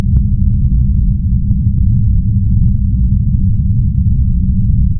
Spaceship Engine
A deep spaceship engine rumble with harmonic resonance and power fluctuations
spaceship-engine.mp3